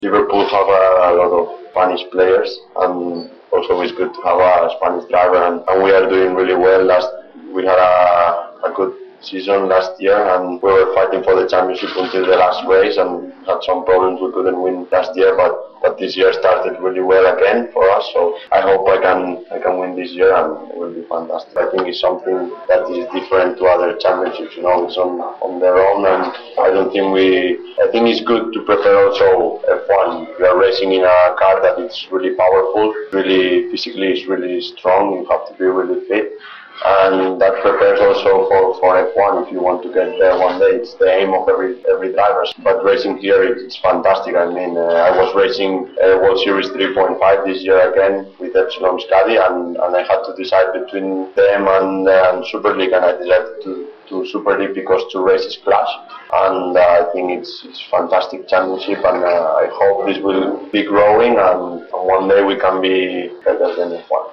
Driver soundbites